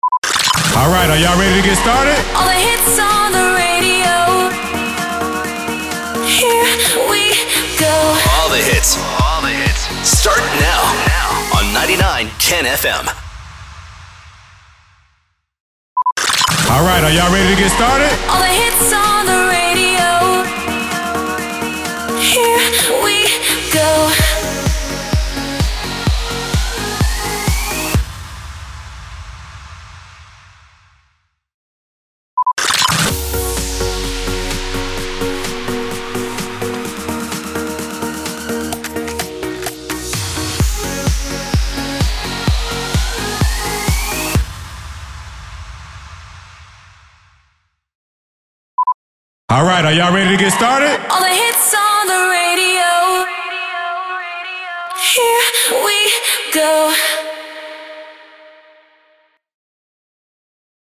757 – SWEEPER – MUSIC REJOIN
757-SWEEPER-MUSIC-REJOIN.mp3